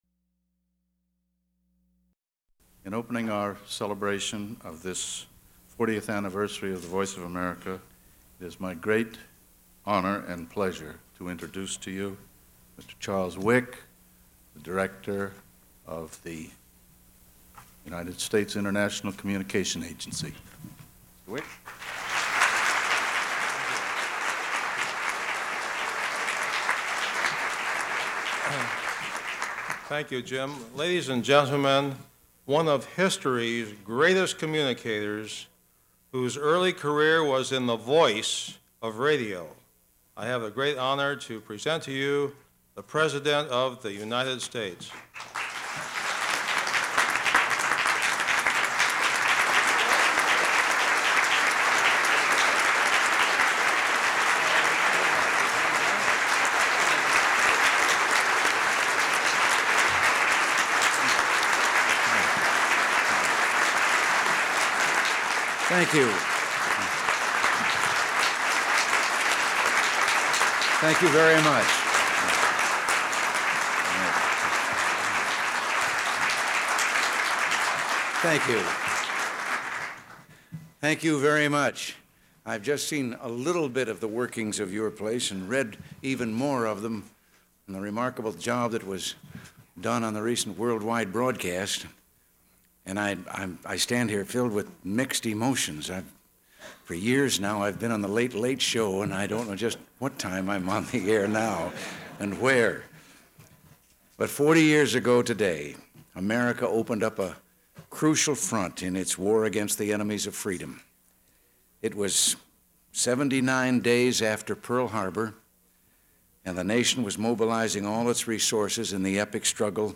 U.S. President Ronald Reagan addresses the Voice of America on its fortieth anniversary